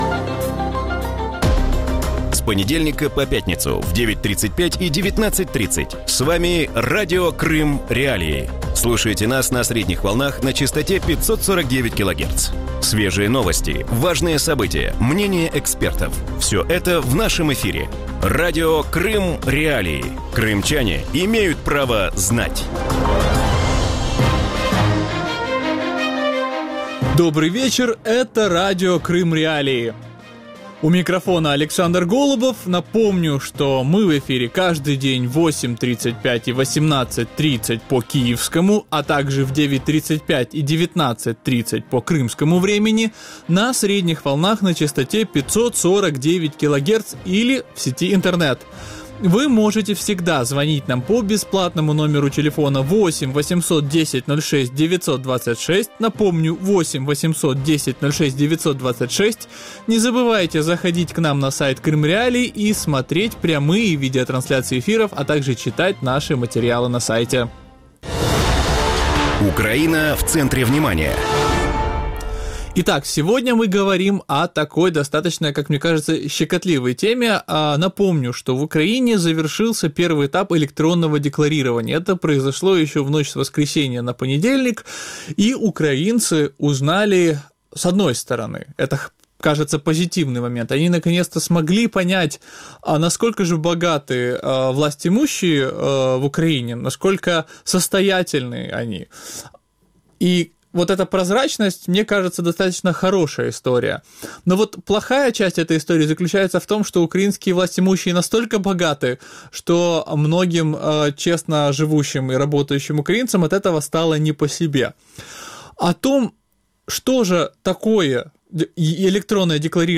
У вечірньому ефірі Радіо Крим.Реалії говорять про українську систему декларування майна та доходів. Чому декларації українських чиновників викликали такий суспільний резонанс, як працює система декларування у російському правовому полі і чим володіють підконтрольні Кремлю керівники півострова?